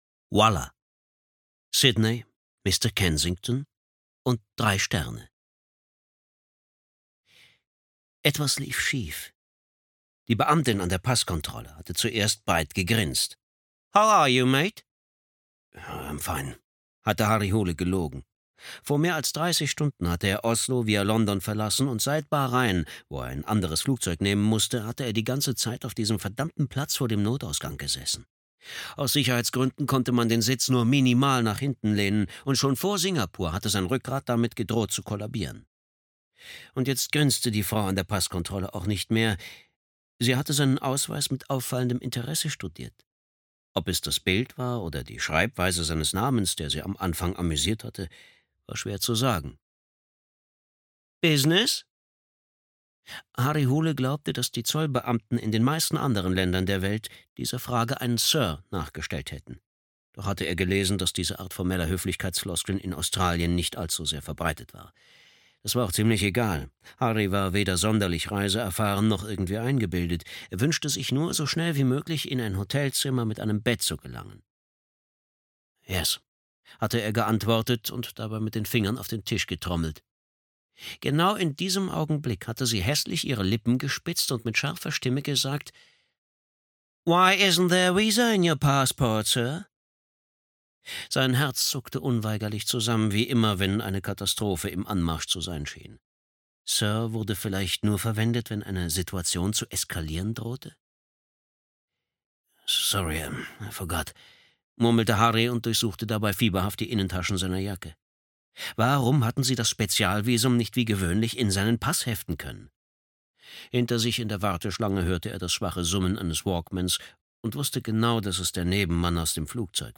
Fledermausmann (DE) audiokniha
Ukázka z knihy